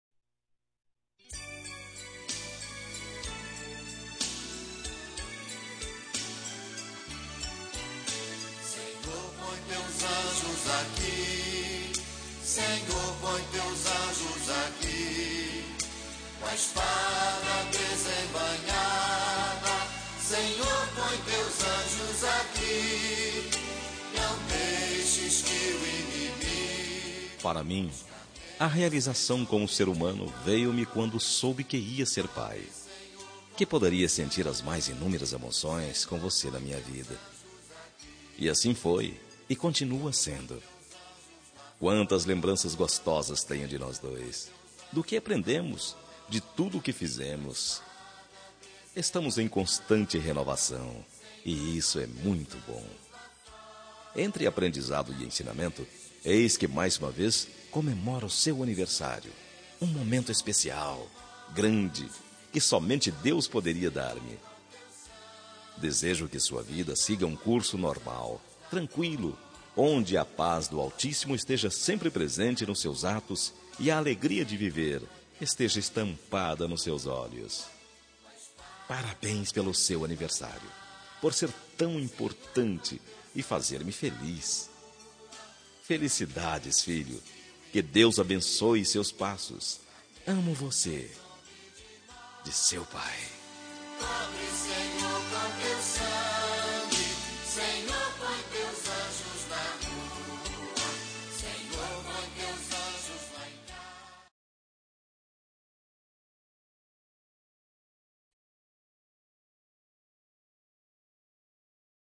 Telemensagem de Aniversário de Filho – Voz Masculina – Cód: 1862 – Religiosa